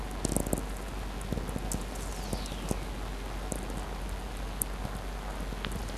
Warsaw, Poland